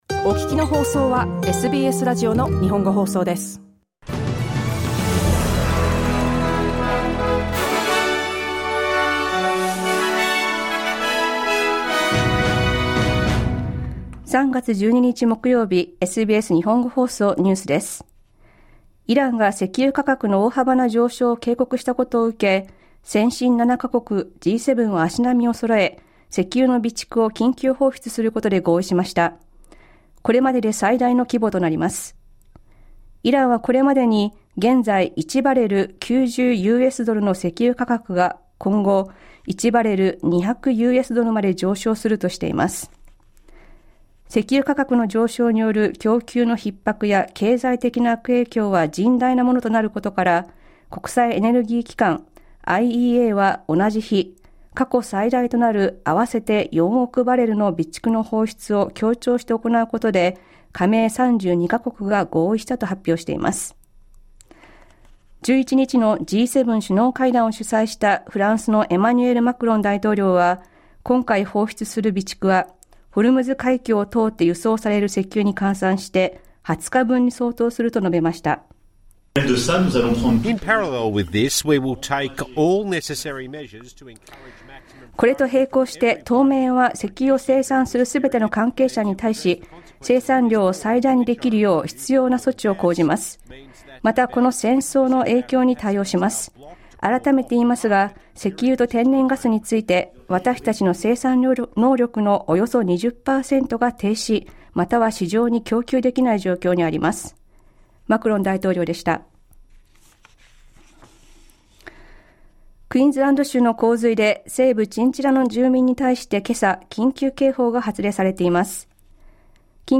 The world's wealthiest nations have agreed to release a record number of barrels of oil from emergency reserves, to ease major price rises coming out of the US and Israeli war with Iran. An emergency alert for flooding is in place this morning for residents of Chinchilla in Queensland's west. News from today's live program (1-2pm).